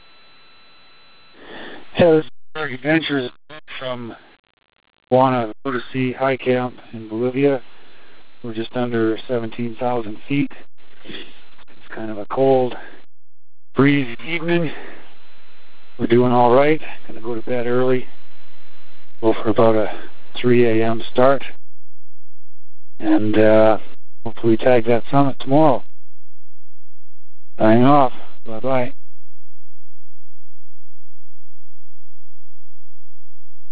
June 21 – Huayna Potosi High Camp